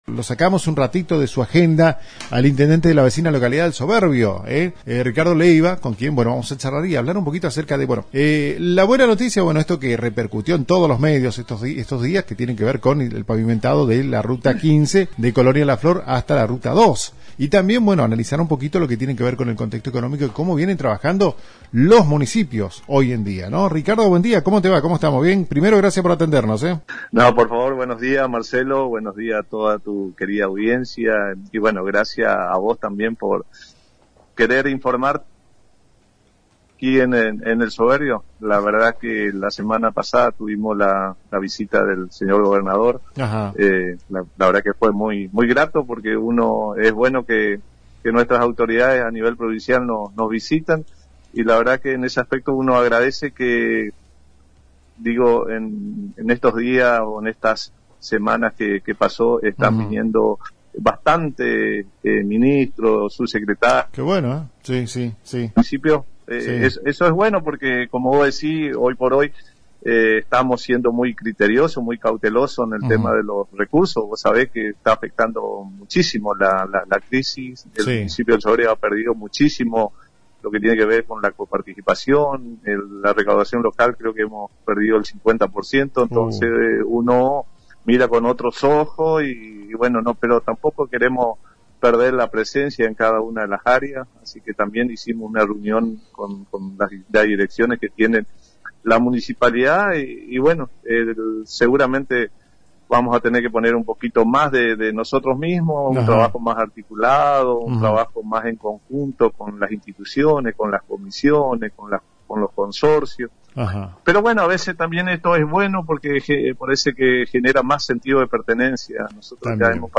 Entrevista: Ricardo Leiva – Intendente de El Soberbio -